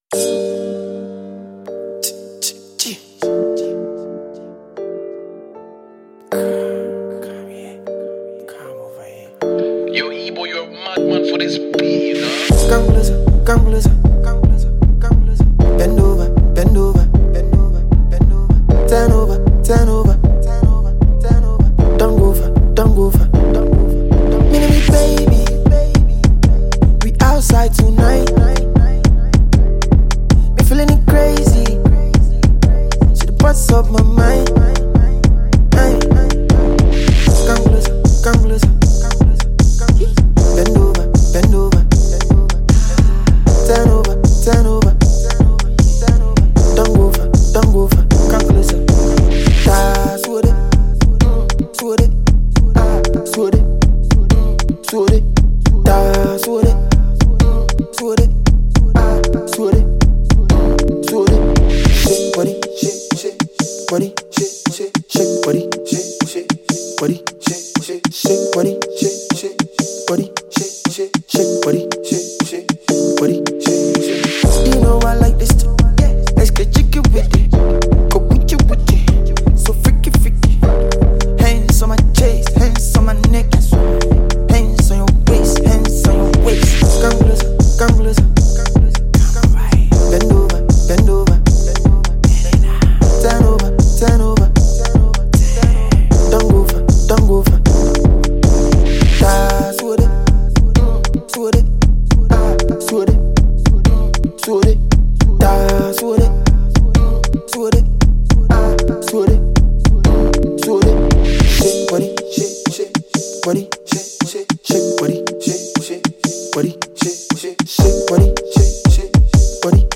a Talented Ghanaian rapper
up-tempo dance/hip-house song
chanting frantically over a synthy & high-BPM beat